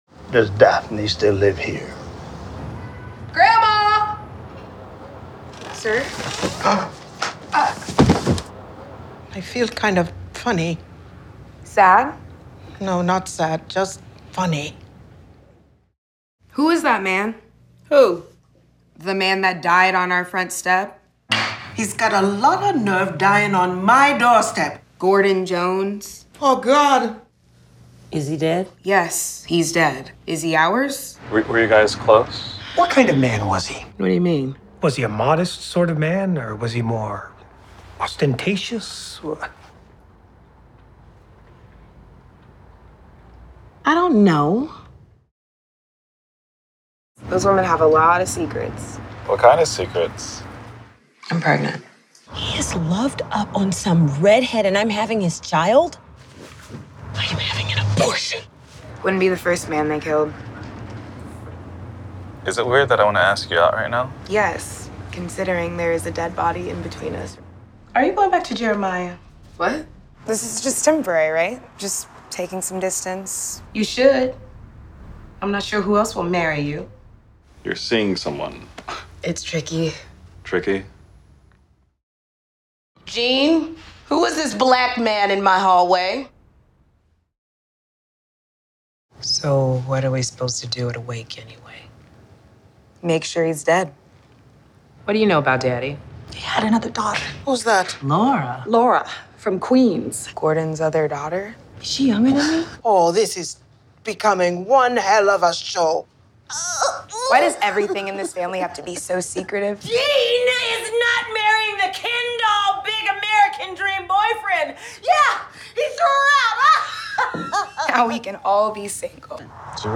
jeanne of the jonese_tca_cut 4 SHORT VERSION_rev1_SOT.wav